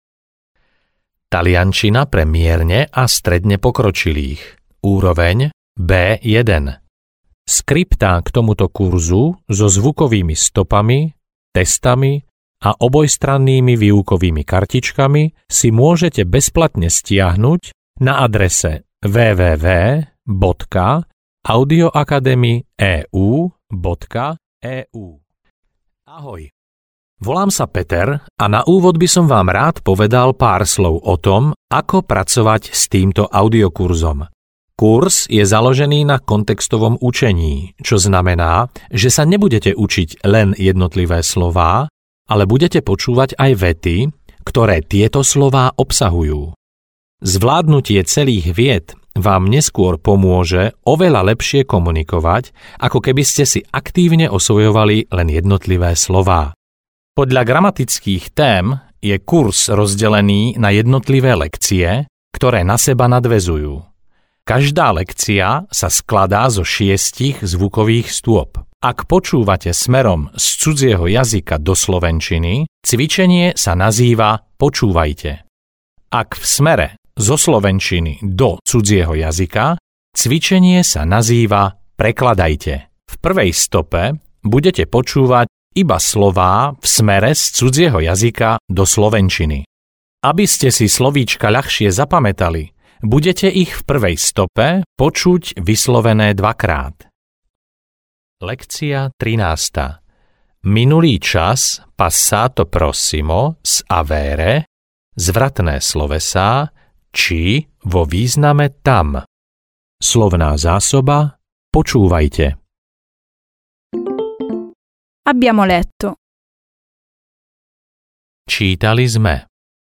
Taliančina - gramatika pre mierne pokročilých B1 audiokniha
Ukázka z knihy